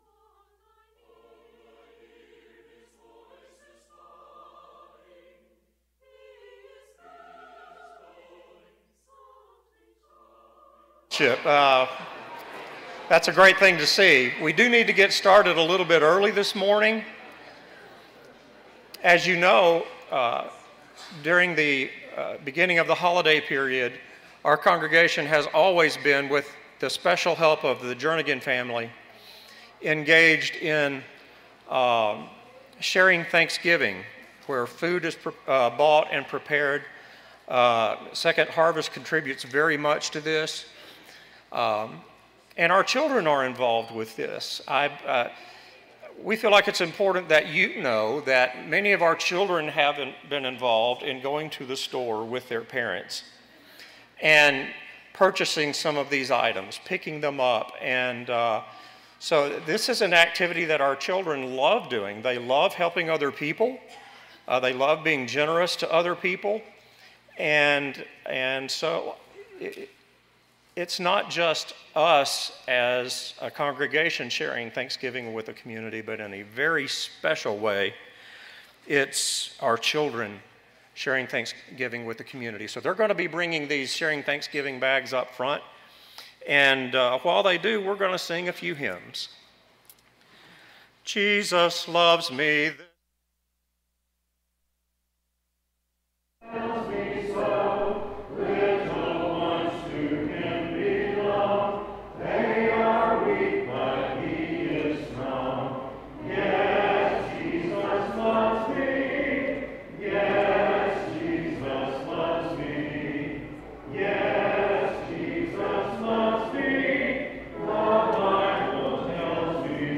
Colossians 1:15, English Standard Version Series: Sunday AM Service